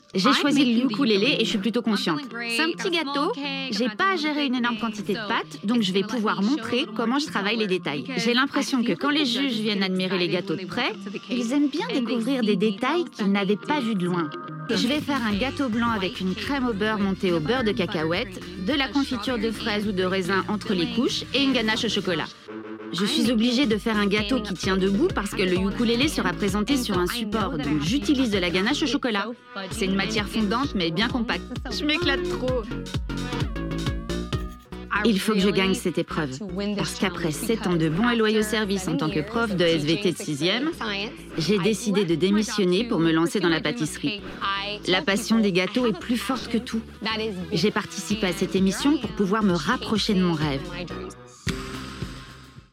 VOICE-OVER (TV réalité) – adulte - medium - présentatrice - souriante/comédie